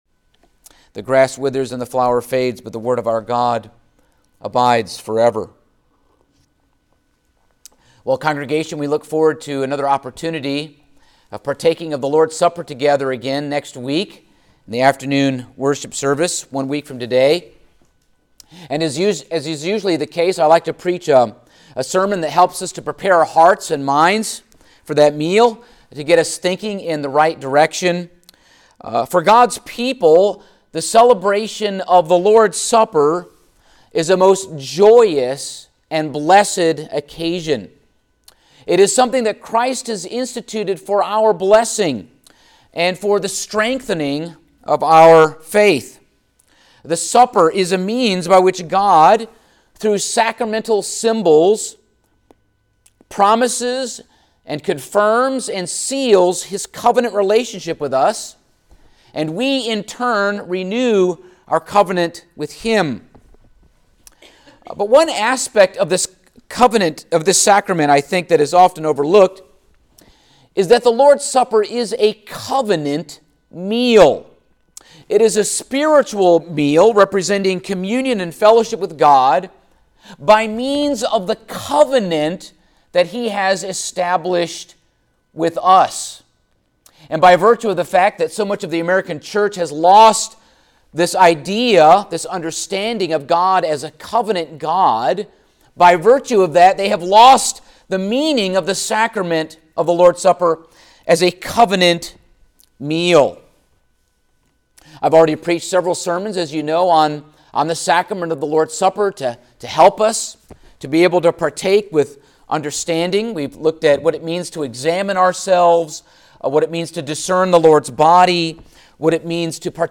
Passage: Exodus 24:1-11, Matthew 26:28 Service Type: Sunday Morning